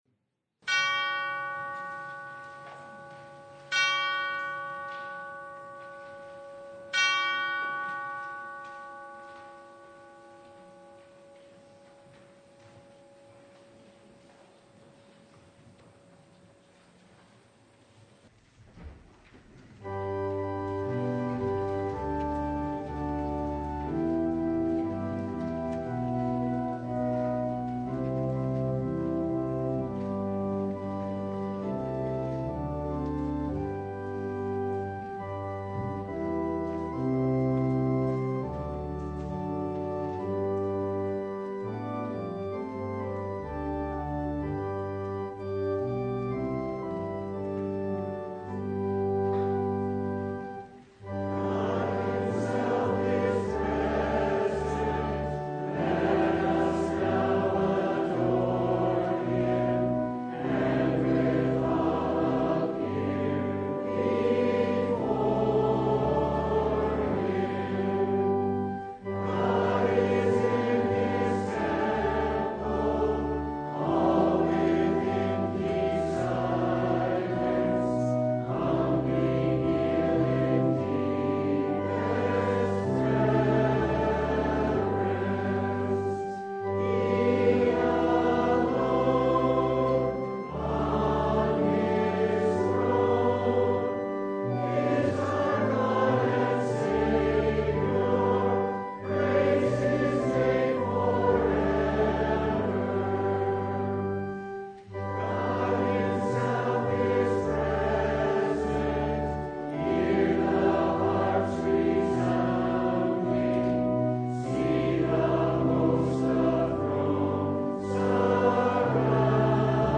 Mark 12:38-44 Service Type: Sunday The poor widow!